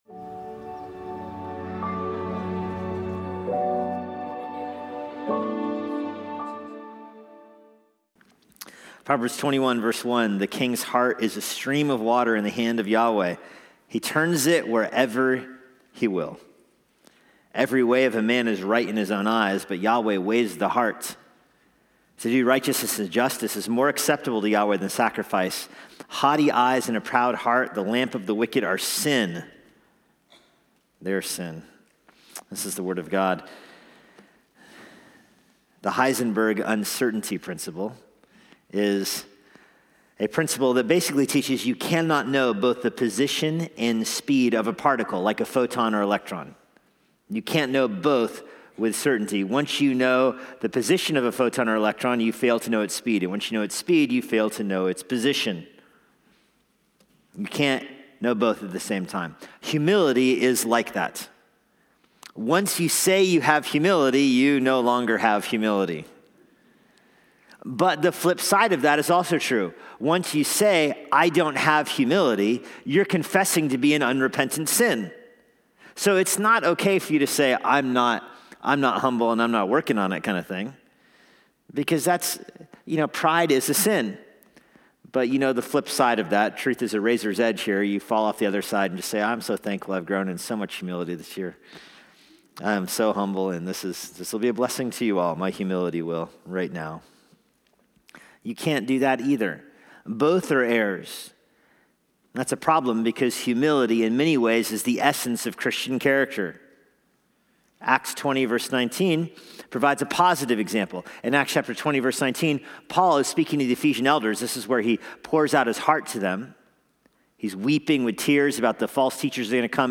Relevant teaching for today from Immanuel Bible Church.